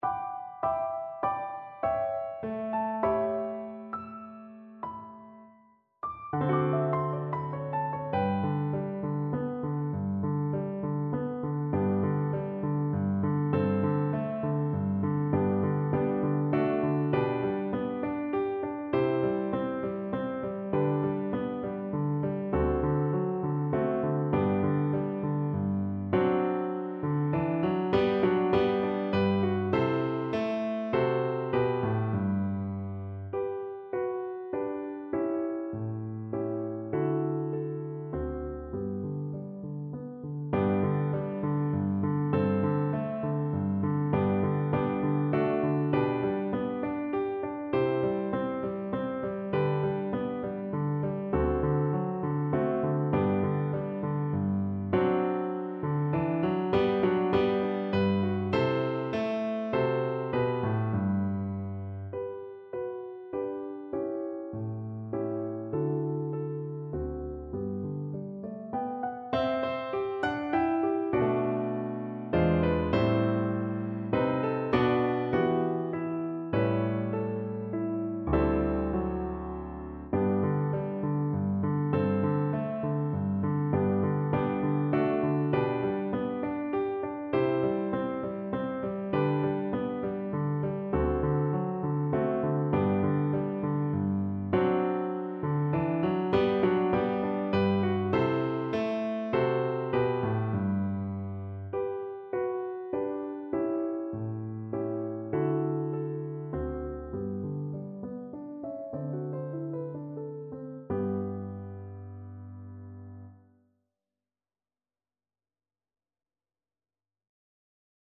Viola
G major (Sounding Pitch) (View more G major Music for Viola )
Tempo rubato
3/4 (View more 3/4 Music)
Traditional (View more Traditional Viola Music)